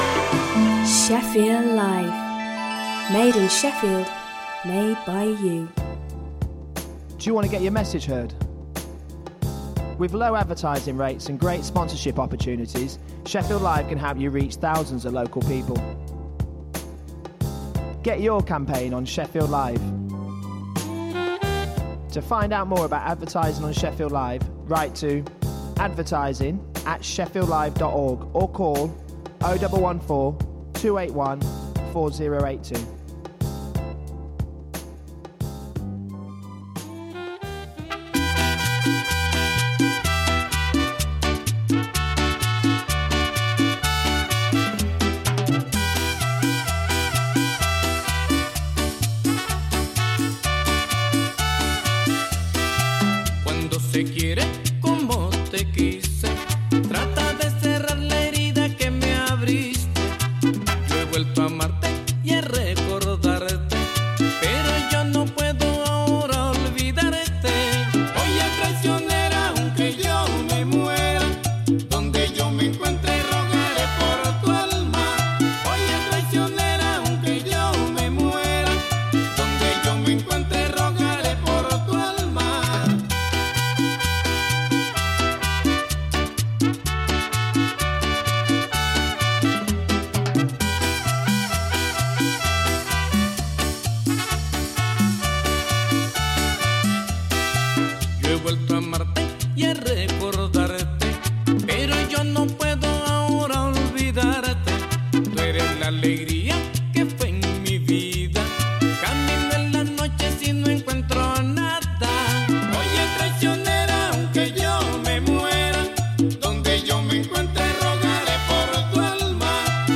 An extensive weekly exploration of modern jazz; be-bop, hard bop, West Coast, Latin, bossa nova, vocals, fusion and soul.